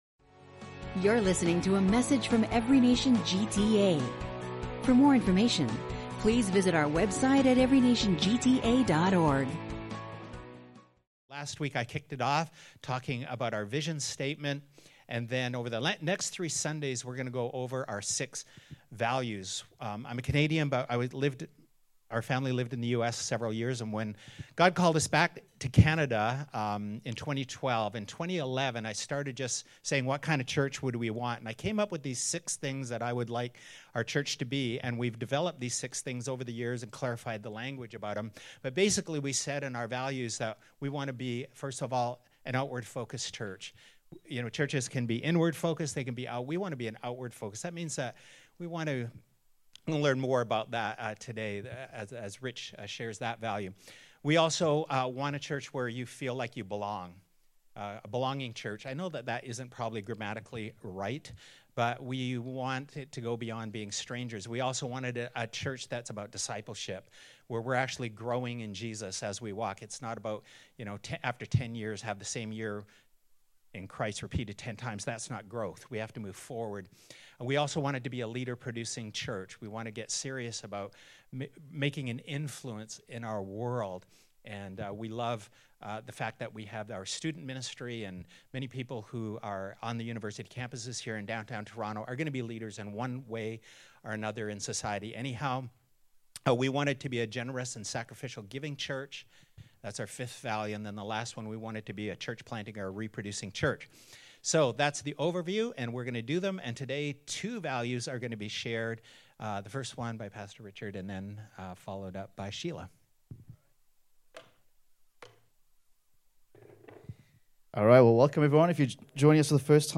In this joint message